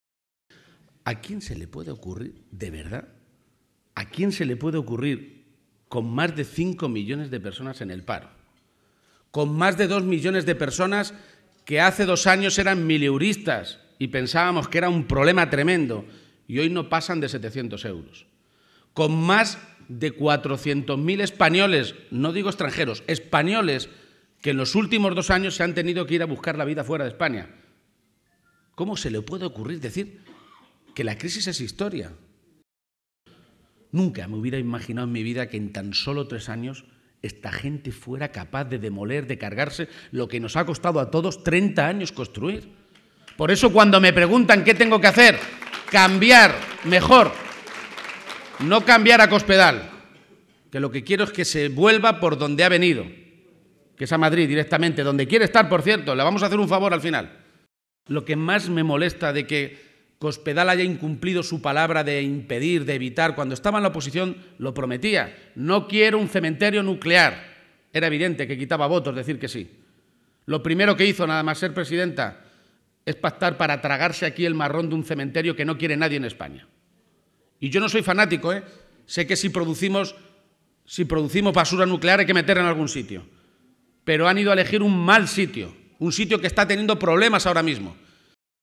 Por la noche, en Villamayor de Santiago (Cuenca), donde Emiliano García-Page compartió comida con militantes y simpatizantes de la comarca de Tarancón, el secretario general del PSCM-PSOE y candidato a la Presidencia de la Junta de Comunidades criticó el optimismo de Rajoy y Cospedal sobre la situación económica de España y Castilla-La Mancha.